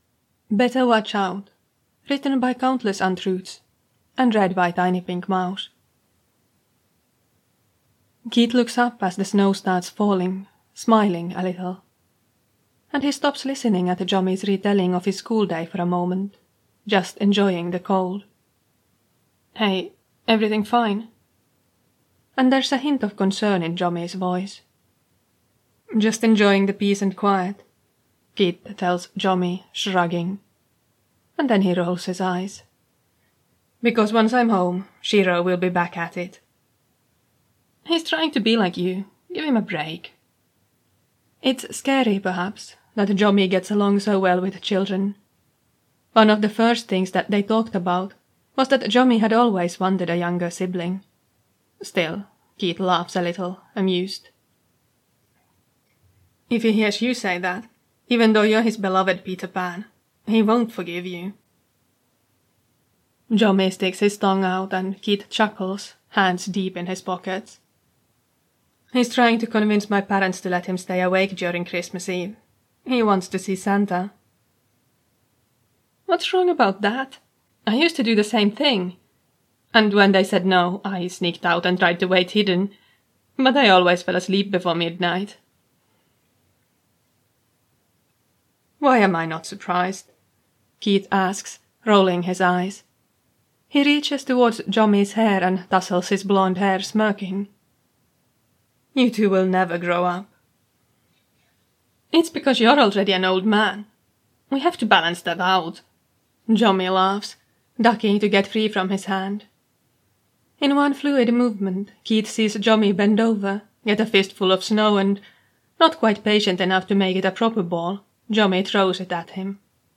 Podfic